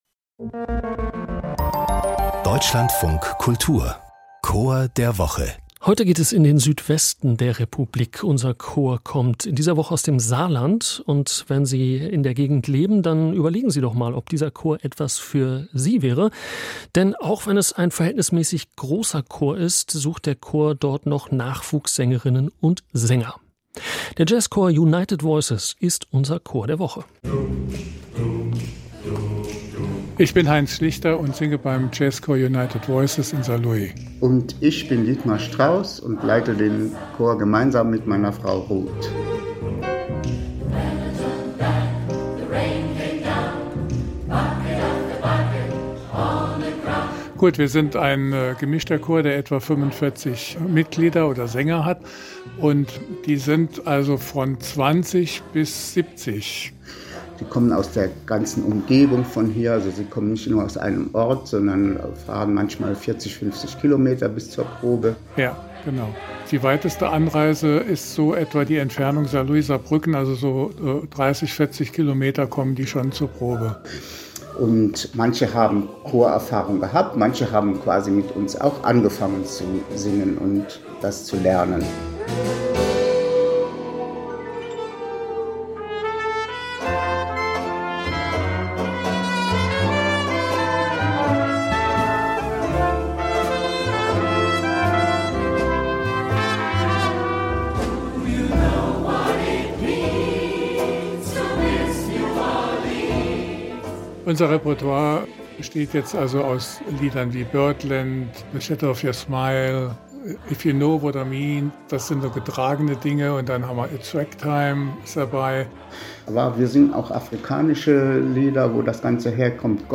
Archiv Jazzchor United Voices 04:04 Minuten © Deutschlandradio Chor der Woche | 31.